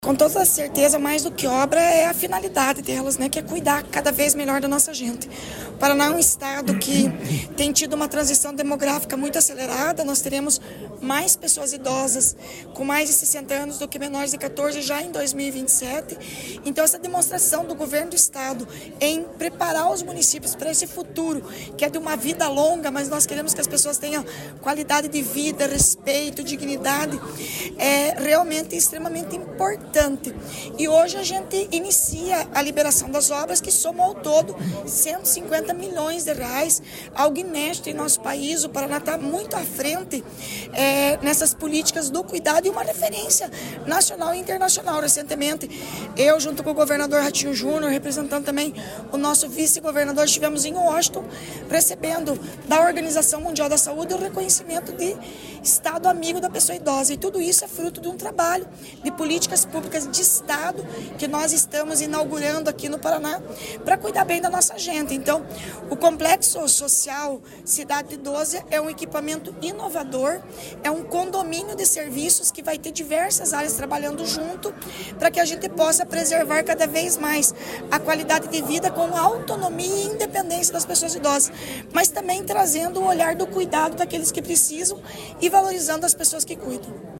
Sonora da secretária da Mulher, Igualdade Racial e Pessoa Idosa, Leandre Dal Ponte, sobre a construção de complexos do idoso em quatro municípios